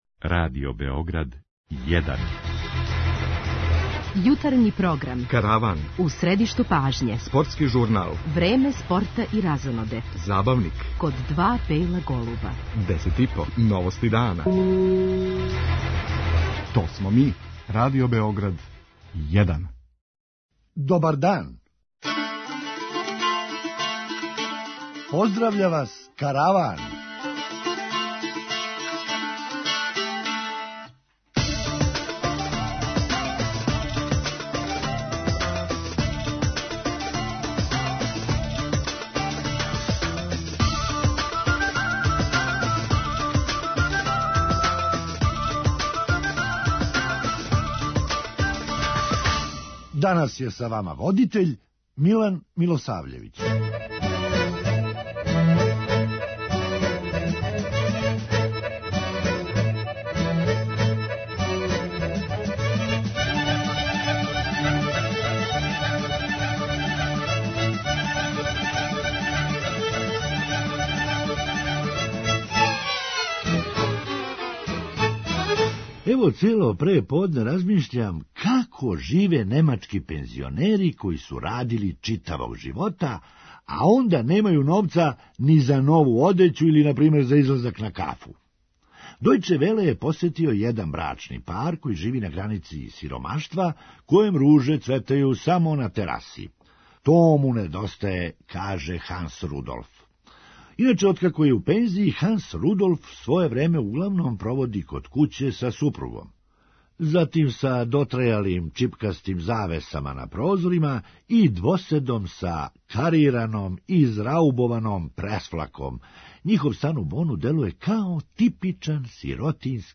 Хумористичка емисија
Народски речено, чуваће нас систем ''ко очи у гĺави''! преузми : 9.01 MB Караван Autor: Забавна редакција Радио Бeограда 1 Караван се креће ка својој дестинацији већ више од 50 година, увек добро натоварен актуелним хумором и изворним народним песмама.